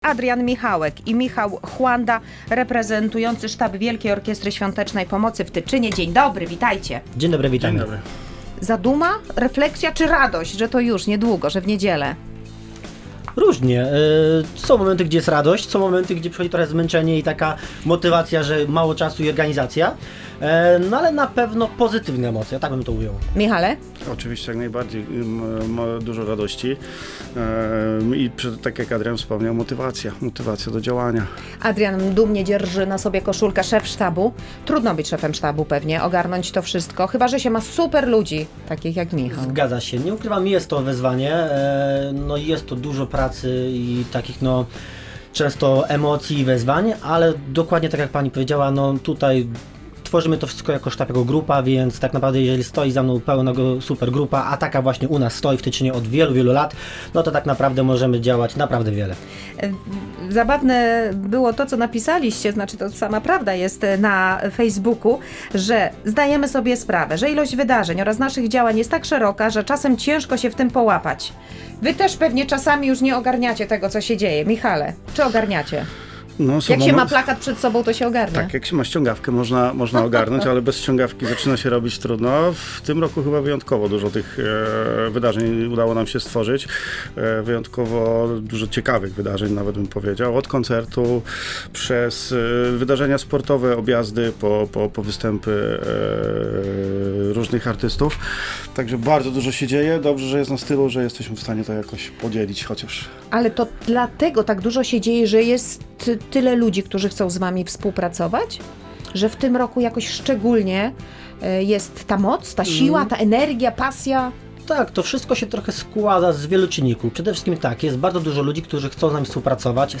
W audycji „Tu i Teraz” rozmawialiśmy z przedstawicielami sztabów Wielkiej Orkiestry Świątecznej Pomocy z Tyczyna. 33. finał WOŚP zbliża się wielkimi krokami – już w najbliższą niedzielę.